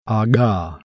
CONSONANTI (NON POLMONARI)